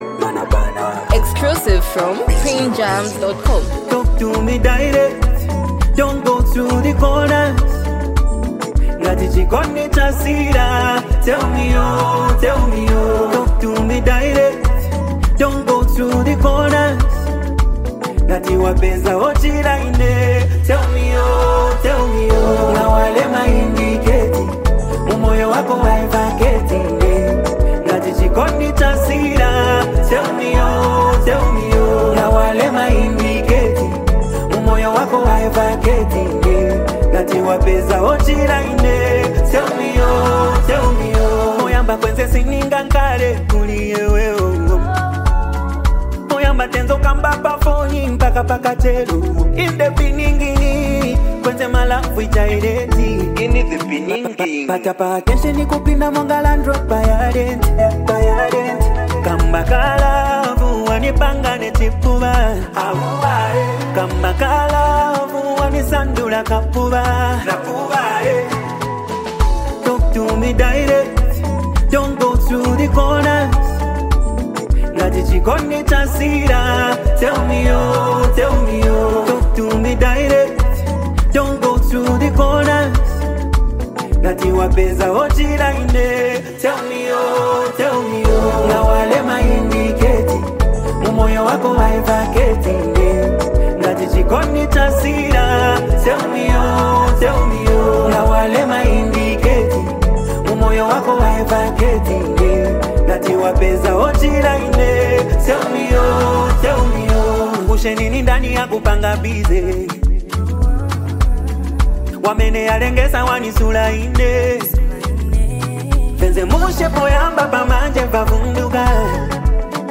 hype, street vibes, and a powerful hook
unique singing flow